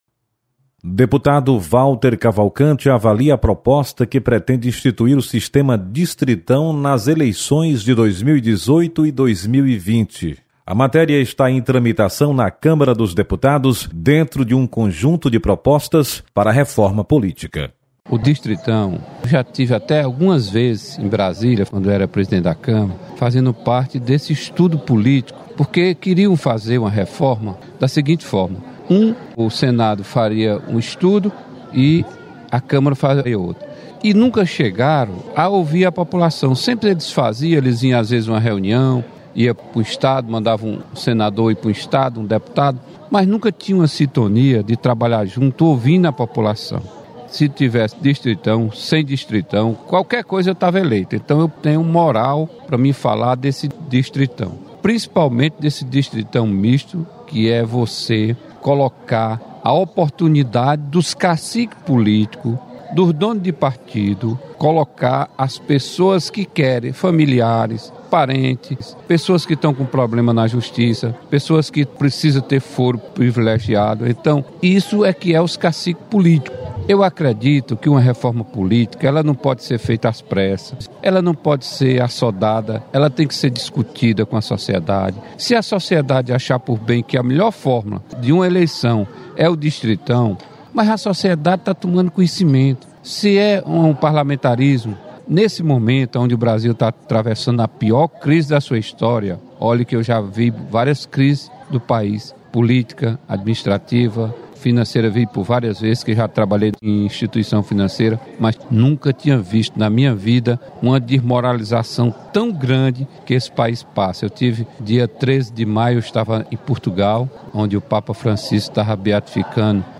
Deputado Walter Cavalcante avalia  adoção do distritão.